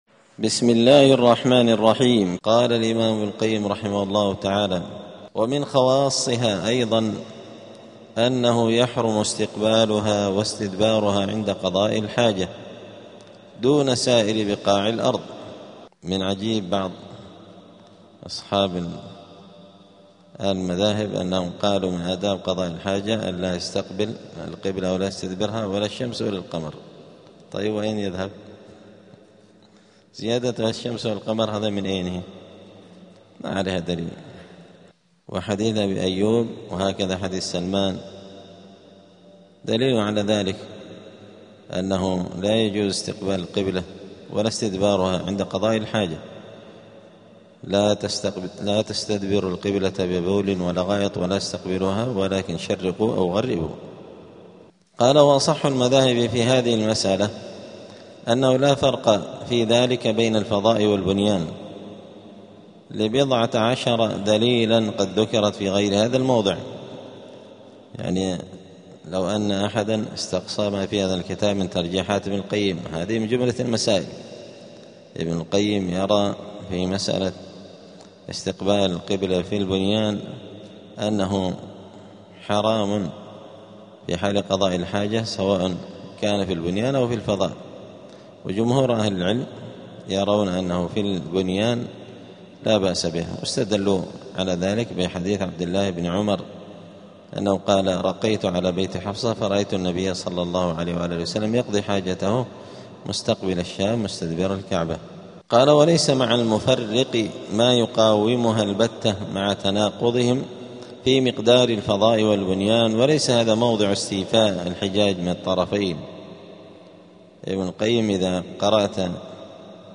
دار الحديث السلفية بمسجد الفرقان قشن المهرة اليمن 📌الدروس الأسبوعية